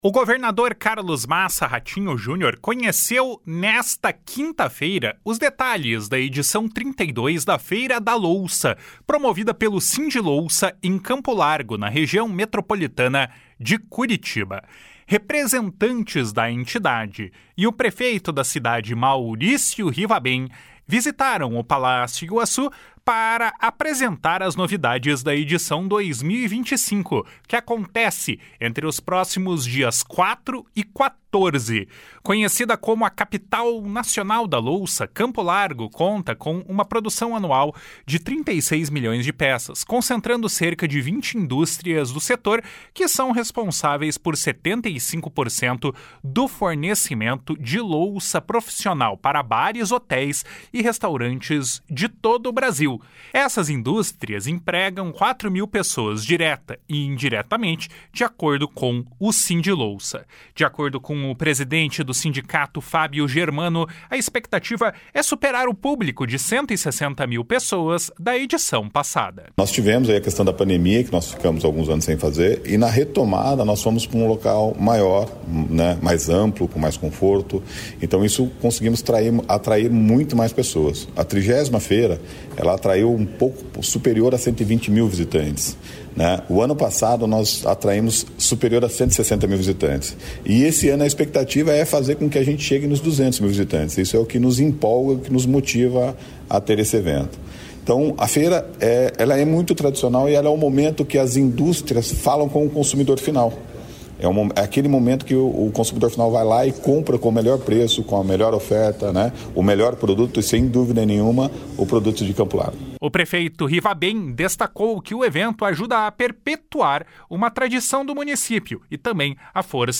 O prefeito Rivabem destacou que o evento ajuda a perpetuar uma tradição do município e a força da economia local.